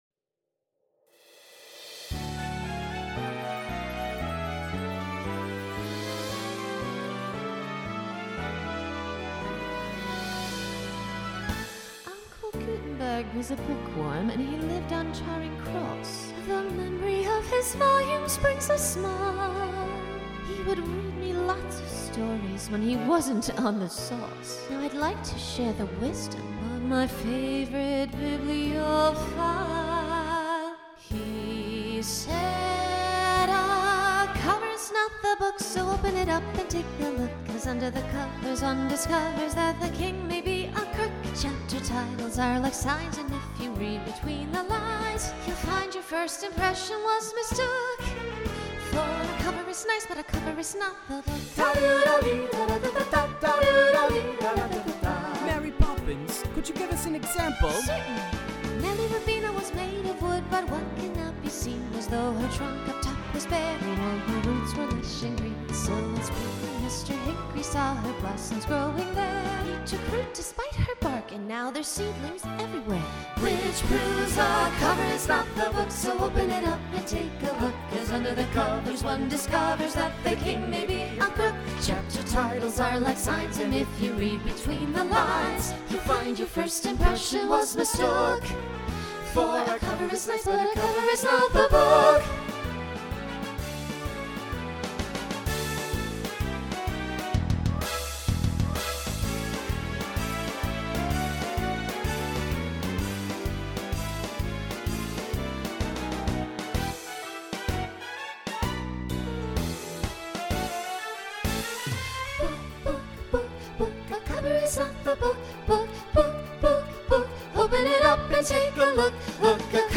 Genre Broadway/Film Instrumental combo
Novelty Voicing SATB